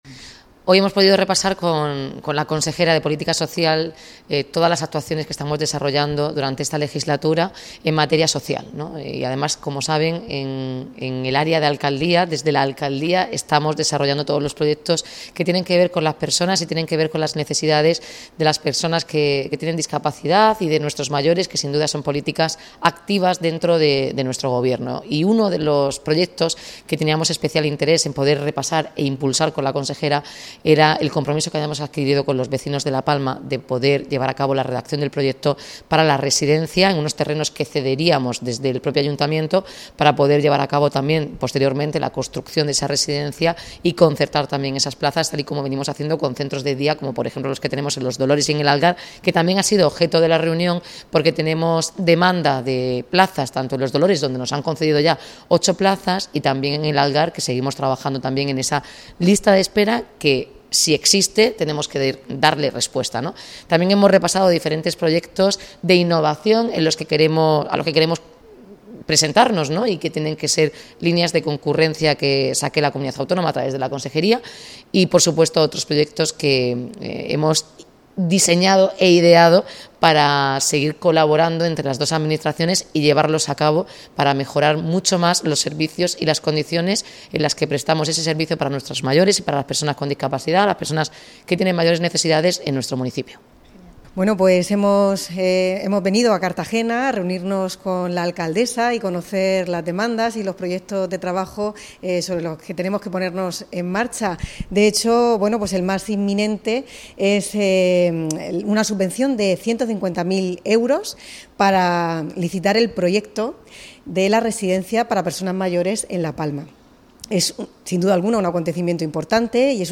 Enlace a Declaraciones de Noelia Arroyo y Concepción Ruiz